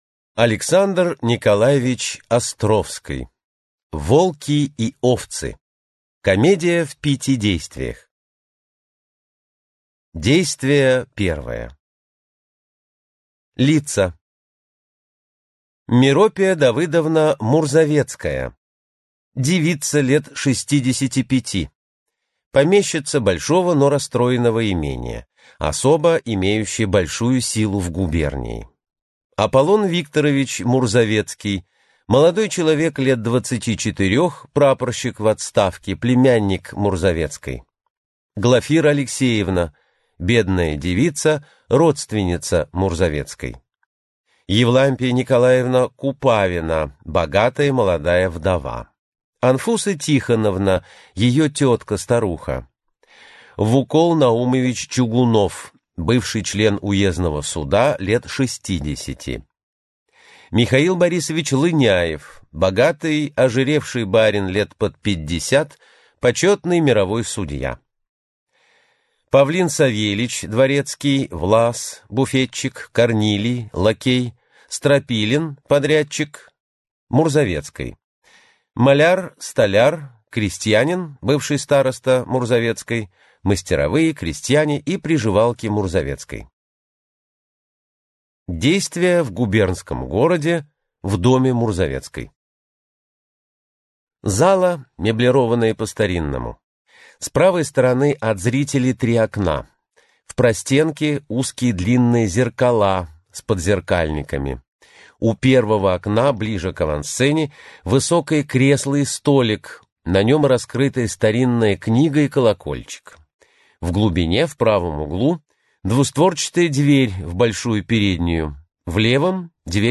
Аудиокнига Волки и овцы | Библиотека аудиокниг